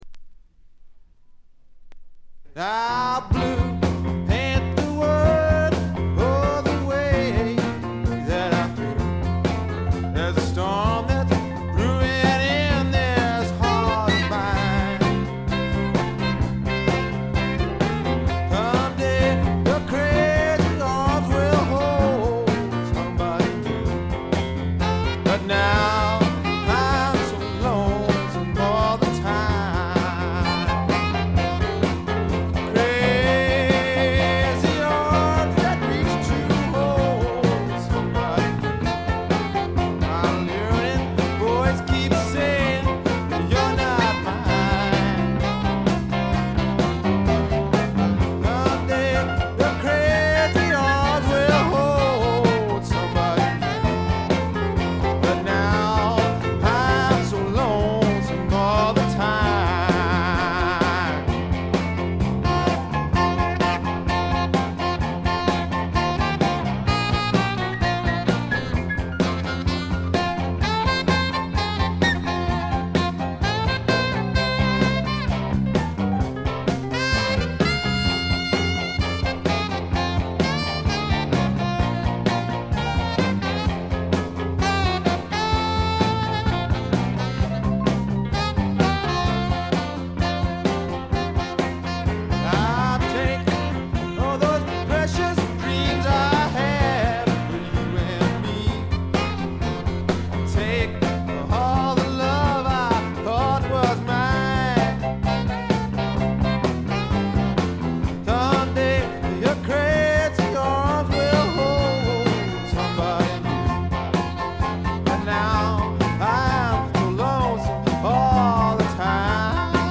部分試聴ですが軽微なチリプチ少し。
試聴曲は現品からの取り込み音源です。